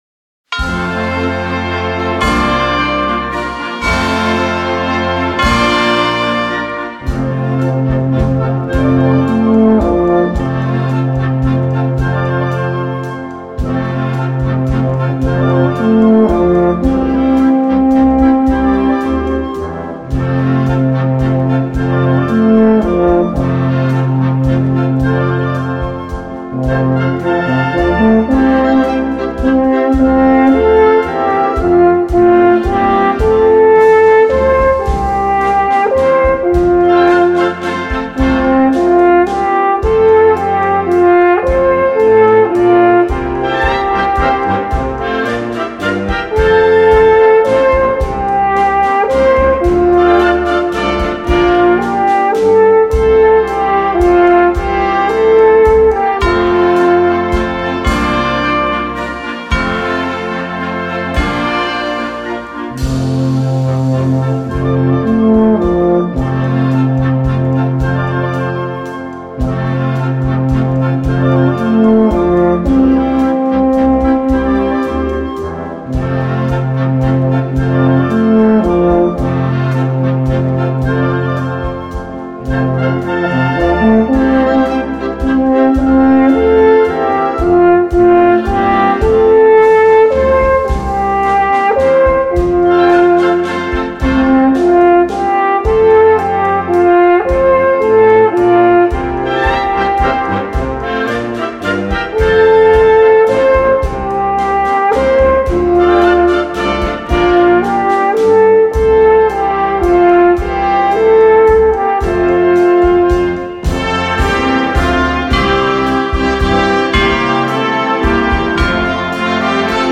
Gattung: für 1, 2 oder 3 Alphörner in F
Besetzung: Instrumentalnoten für Alphorn